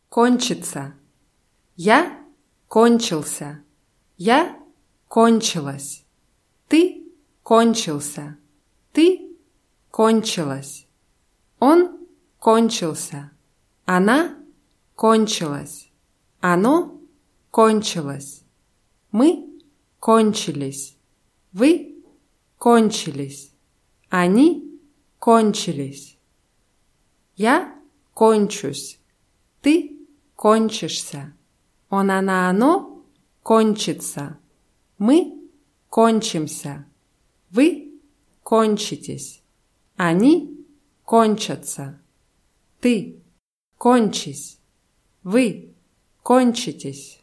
кончиться [kóntschitsa]